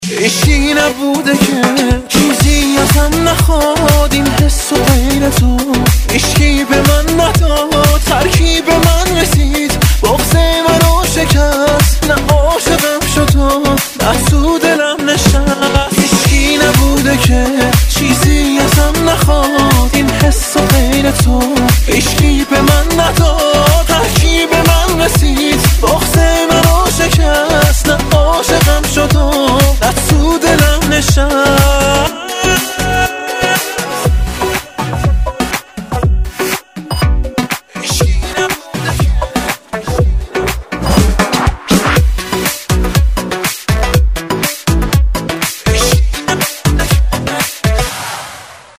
رینگتون ریتمیک (با کلام)